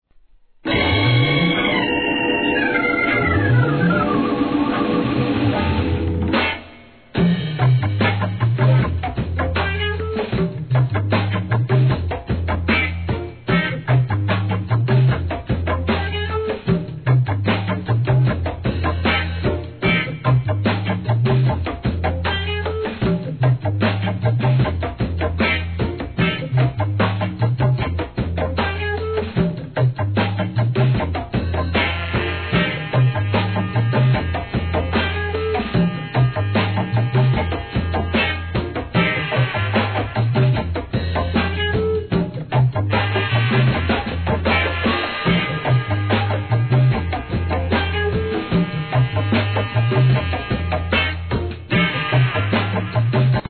SOUL/FUNK/etc...